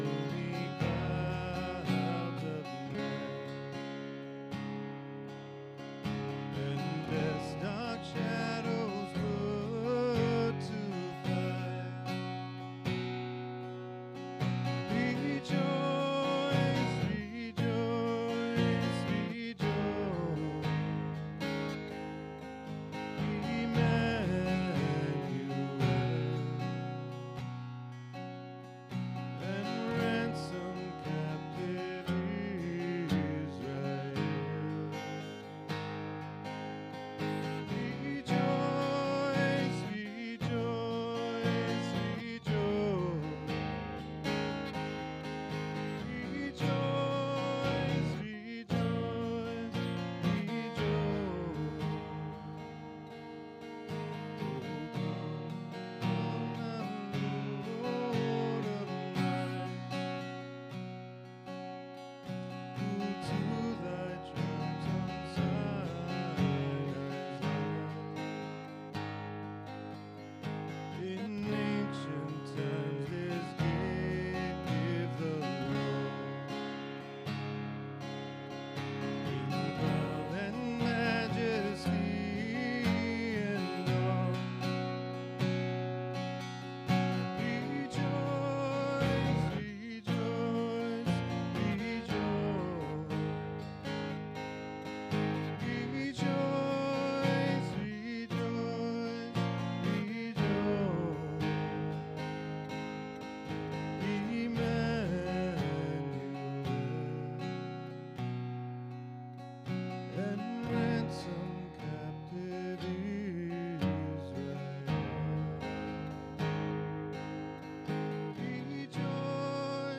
SERMON DESCRIPTION Delve into the message of the angels proclaiming peace on earth and good will to all.